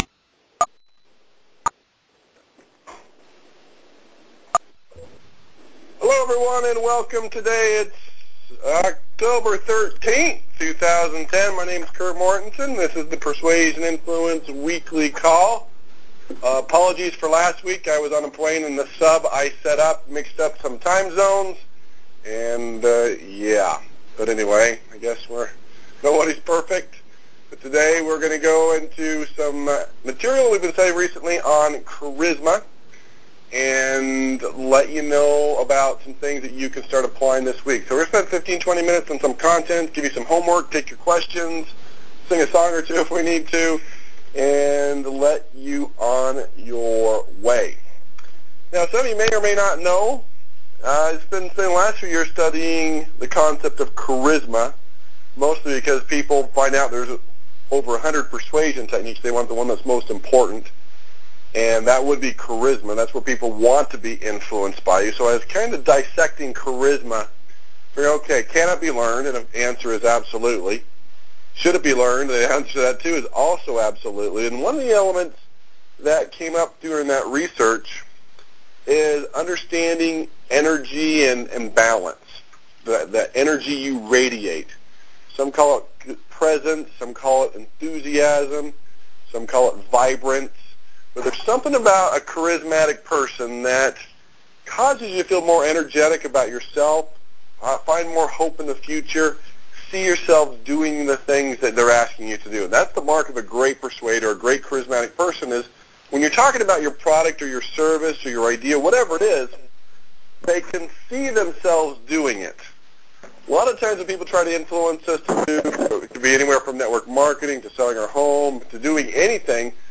‹ Personalities Personality › Posted in Conference Calls